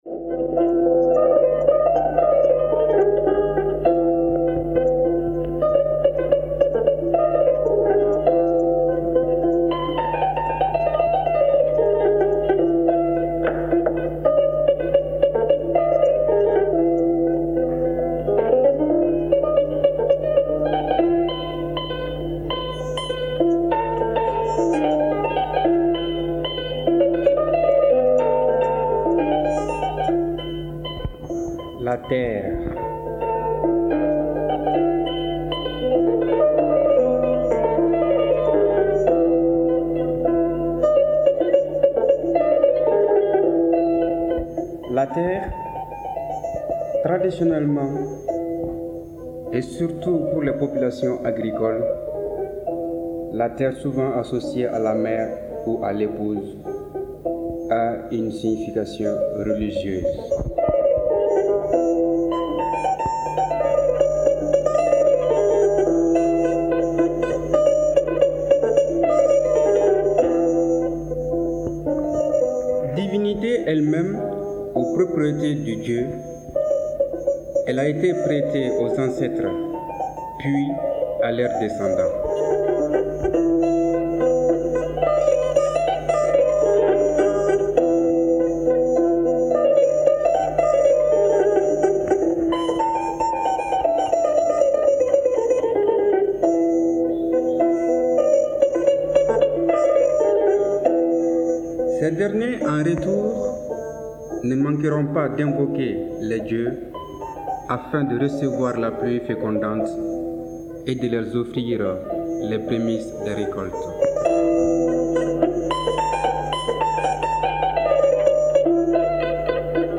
La terre : Conte malien · OmekaS By DataCup · Omekas - Mali